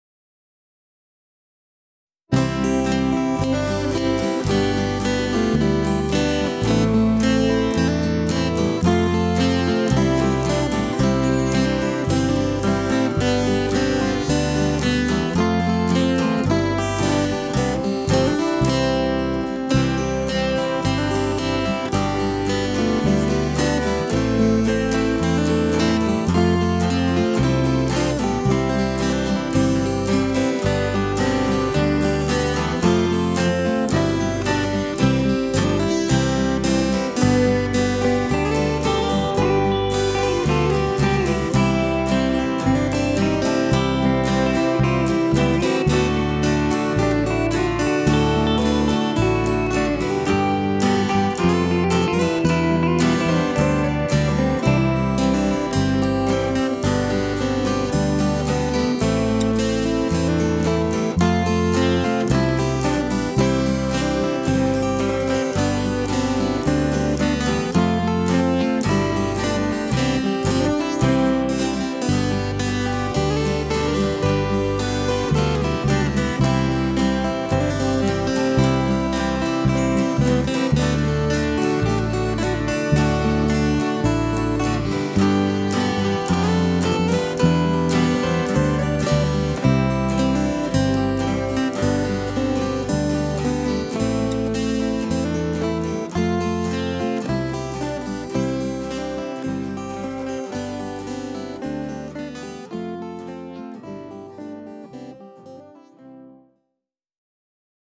Recorded at River Road Recording Studio, Ottawa, ON